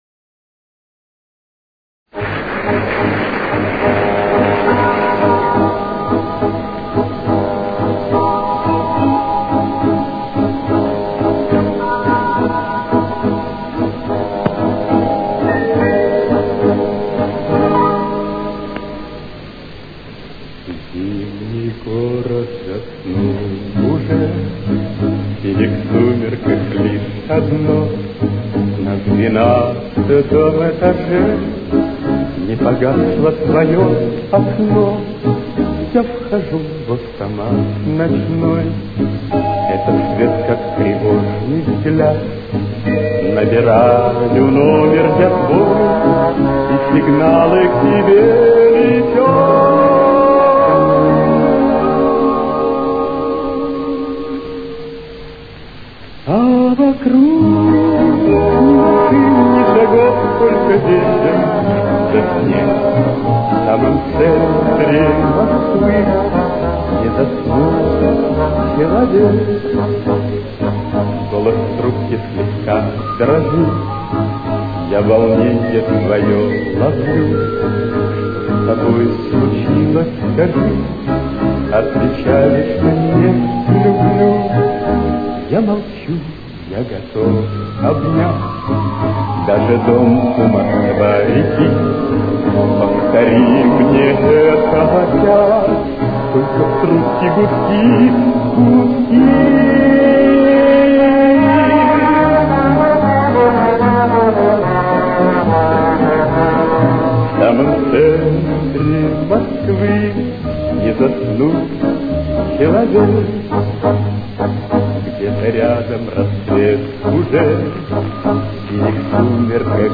Темп: 207.